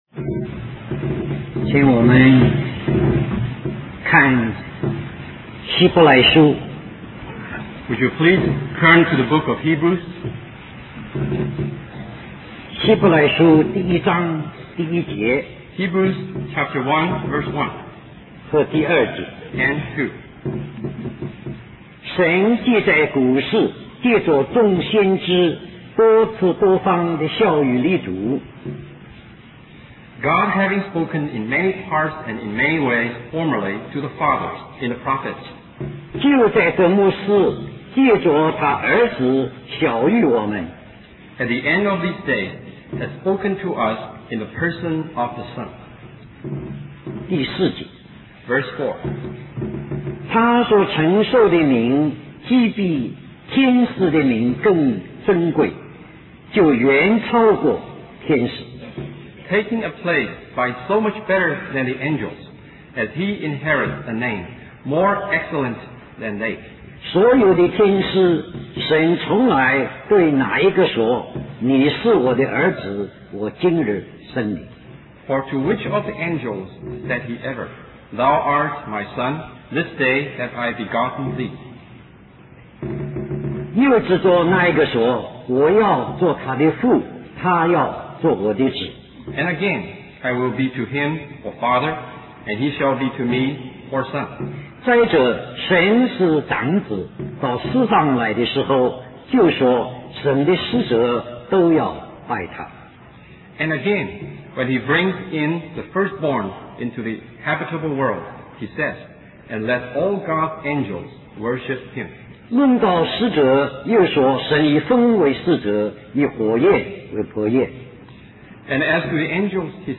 A collection of Christ focused messages published by the Christian Testimony Ministry in Richmond, VA.
West Coast Christian Conference